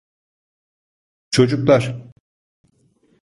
/tʃo.dʒukˈlaɾ/